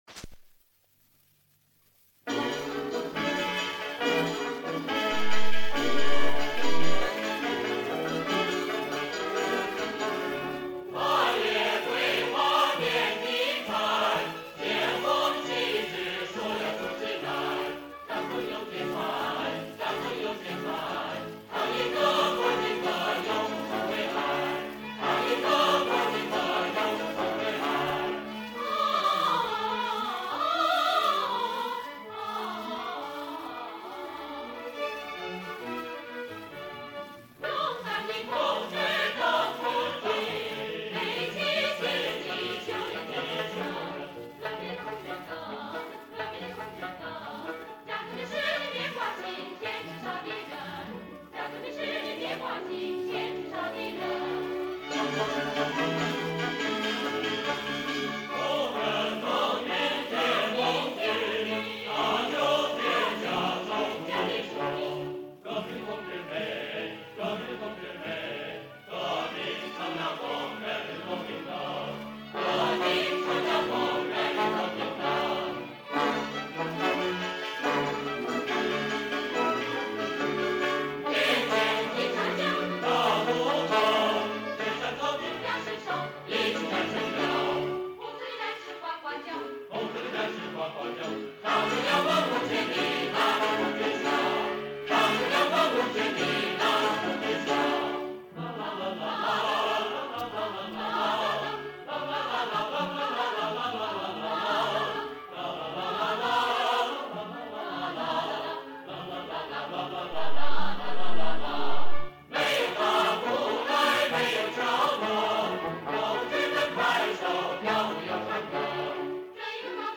（中国工农红军歌曲）